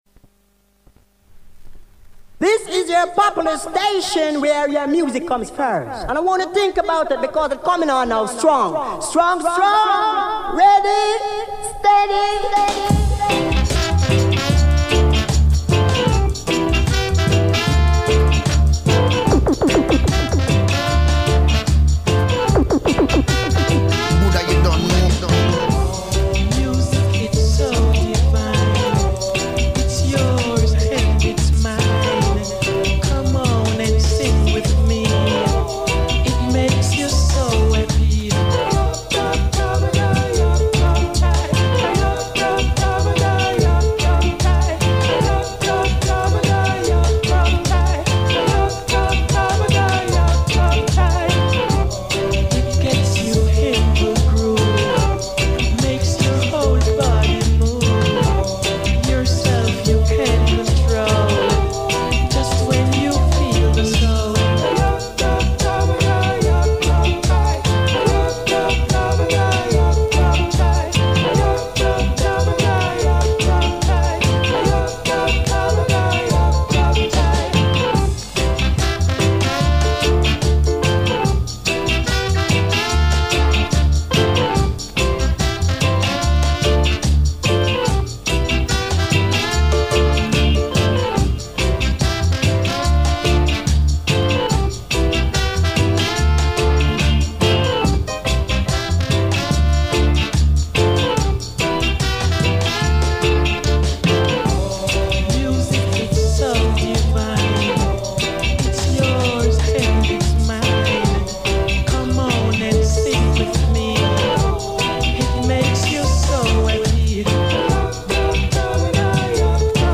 100% VINYL